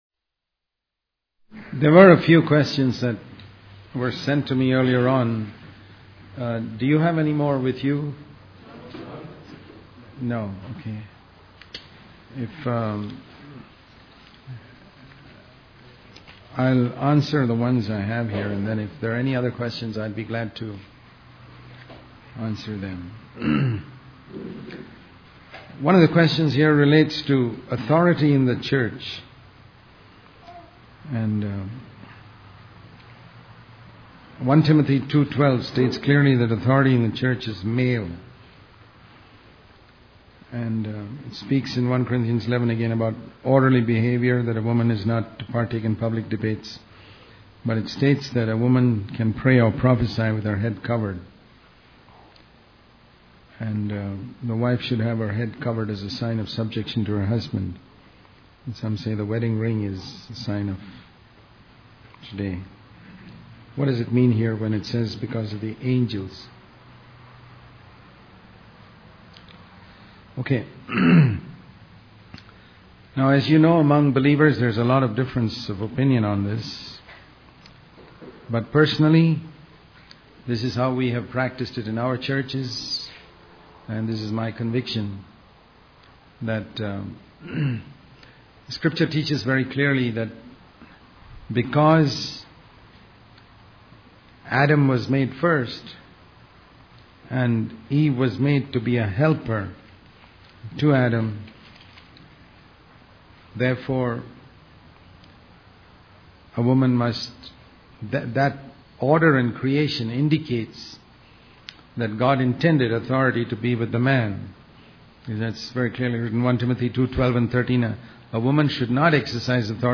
In this sermon, the speaker discusses the importance of fellowship in the church. He emphasizes that when people come to a church, they should not only hear the message but also experience the spirit of fellowship. The speaker shares a story about a boy selling peanuts who cleverly entices people to buy by eating the peanuts himself.